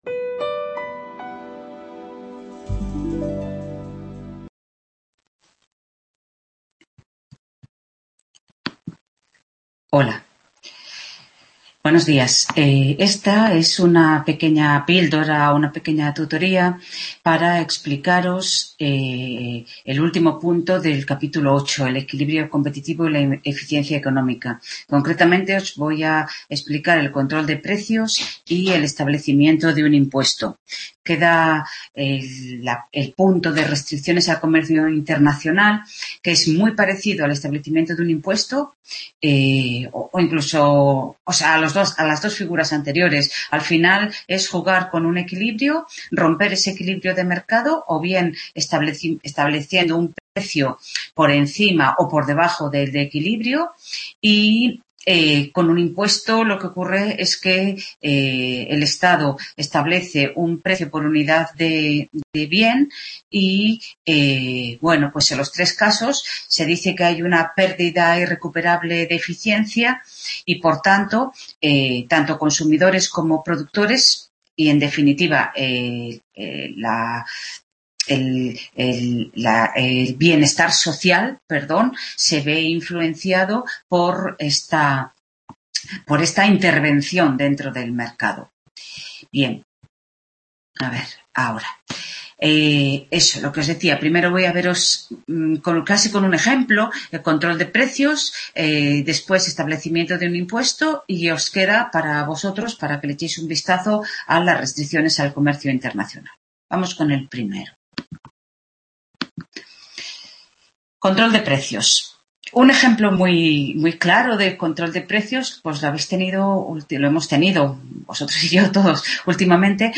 Tutoría Microeconomía 2º ADE (tema 8.8 control de precios e impuestos)